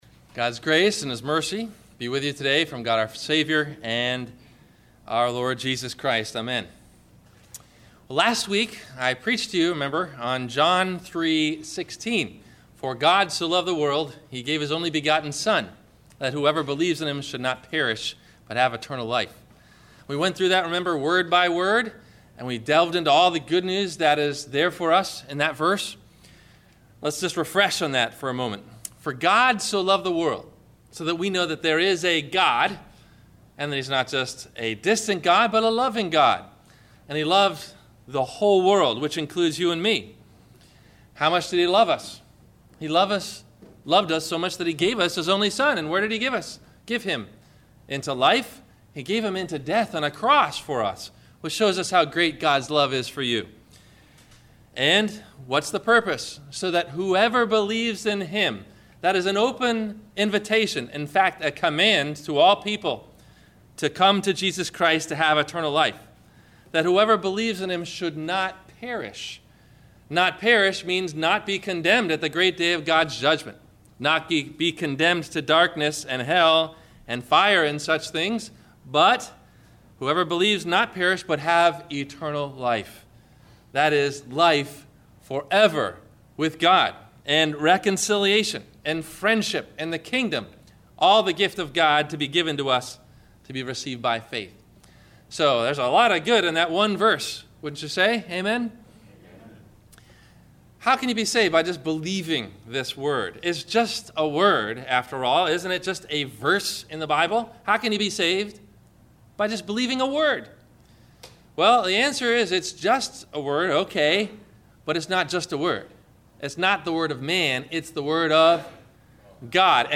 Listen for these questions and answers about A Wrong and A Right Way to Respond to the Gospel, below in the 1-part Video or 1-part MP3 Audio Sermon below.